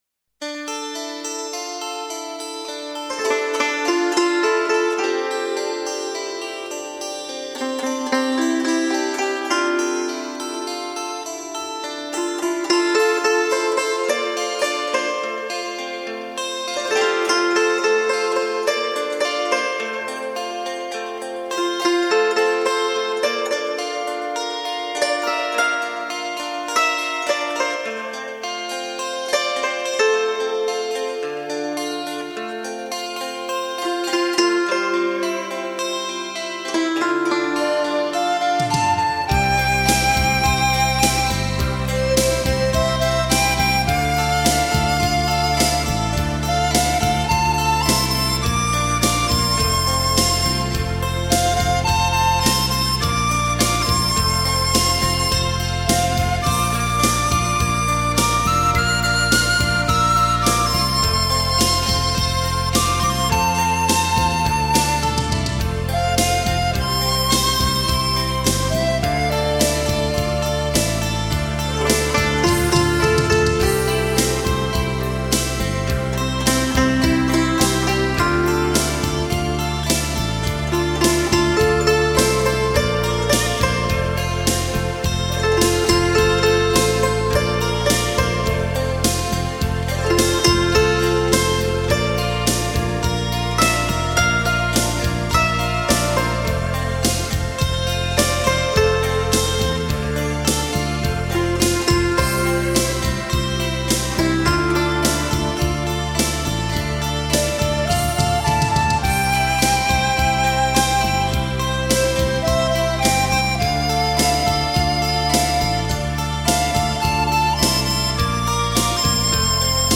专辑音乐：二胡, 古筝, 笛子